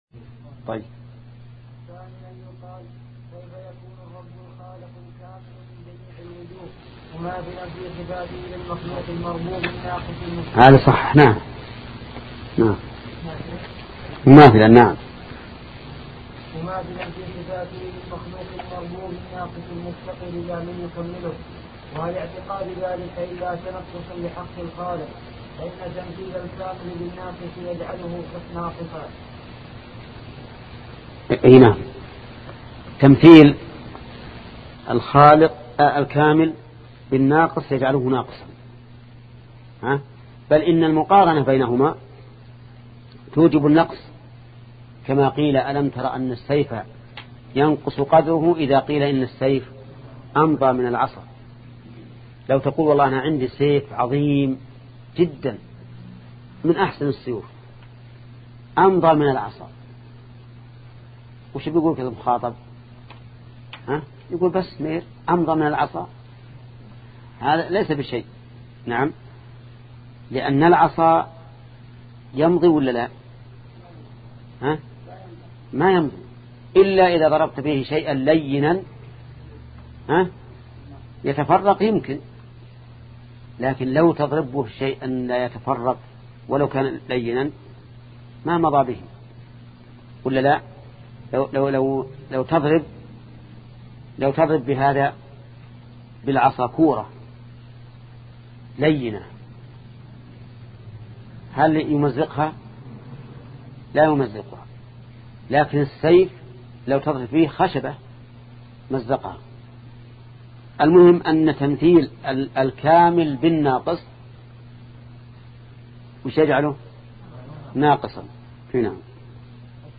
سلسلة مجموعة محاضرات شرح القواعد المثلى لشيخ محمد بن صالح العثيمين رحمة الله تعالى